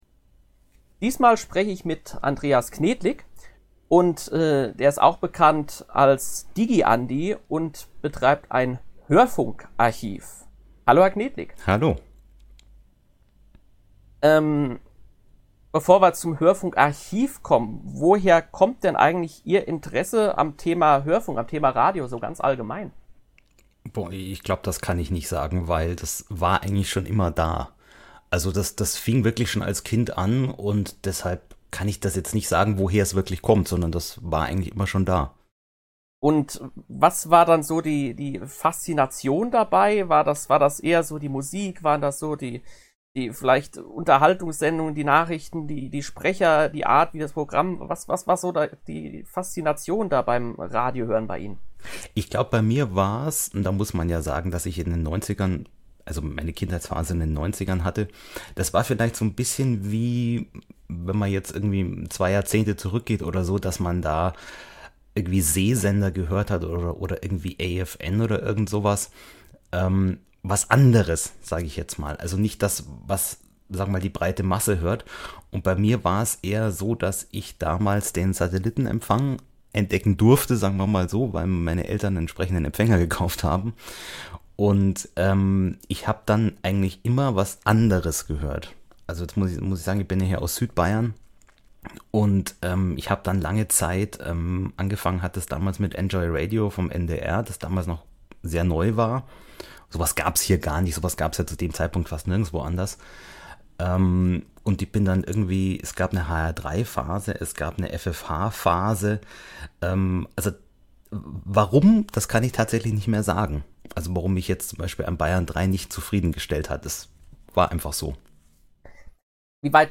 Der Gesprächs-Podcast